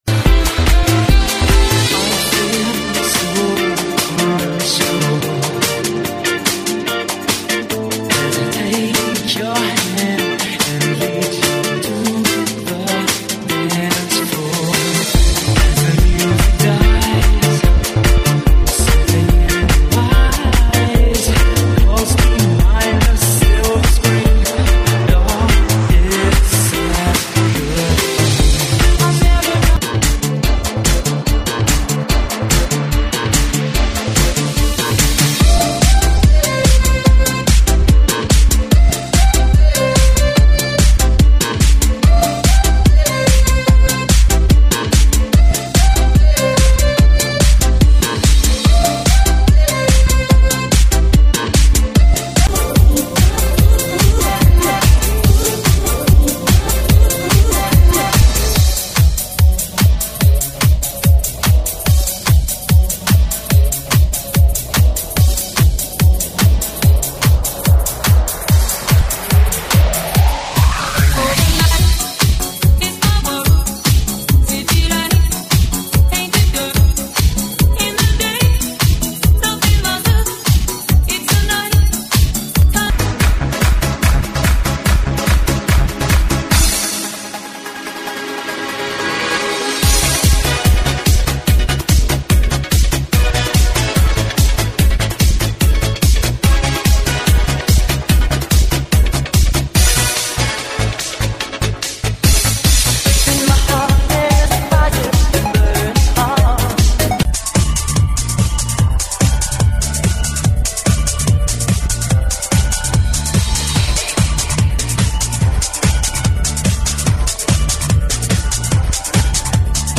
GENERO: MUSICA DISCO, 80S, INGLES RETRO